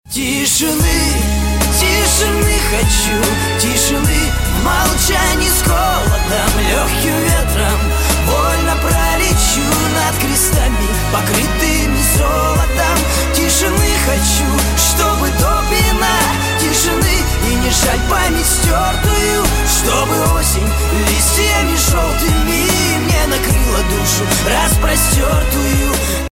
• Качество: 320, Stereo
мужской вокал
лирика
грустные